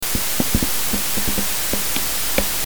Привет, прокомментируйте - плиз карта - уровень входа в винде 63, уровень гейна на 10 часов, во вход ничего не включено, при записи, если потсукивать по карте или по гнезду входа - получаем щелчки. звук после записи нормализован.